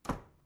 Close Closet.wav